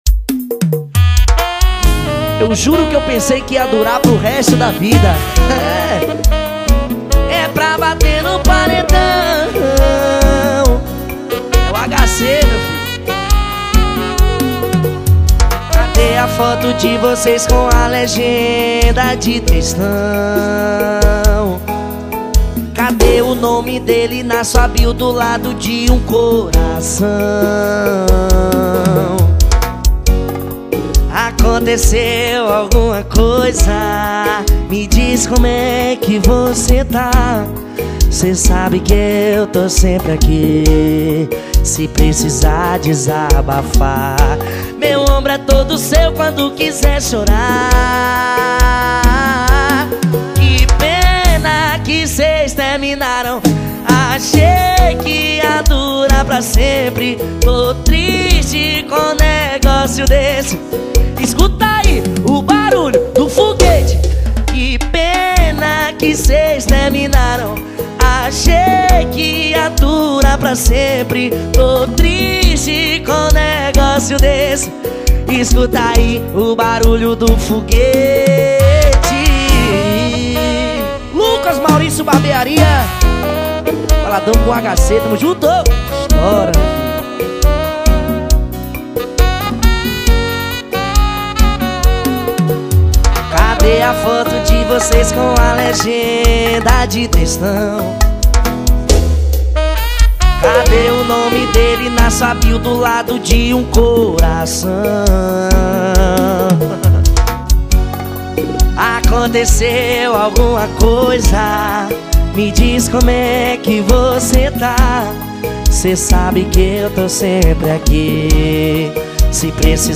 2024-07-09 15:39:46 Gênero: Forró Views